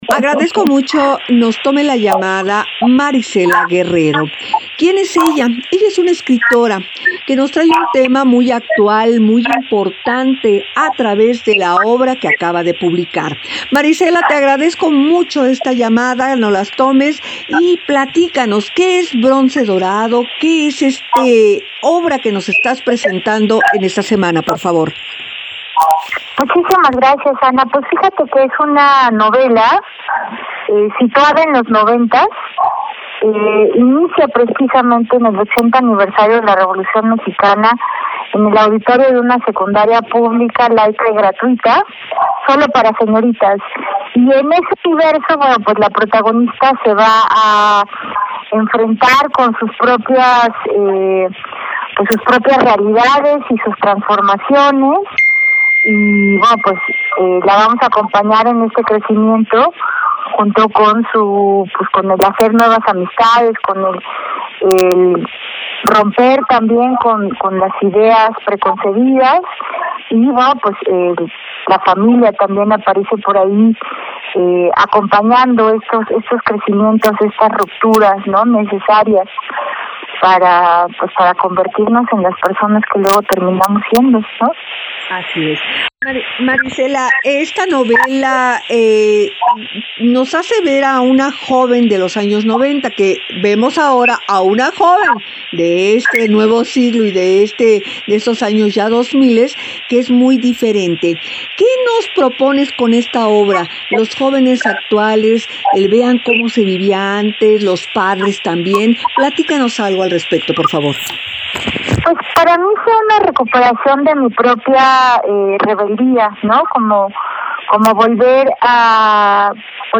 ENTREVISTA-BRONCE-DORADO.mp3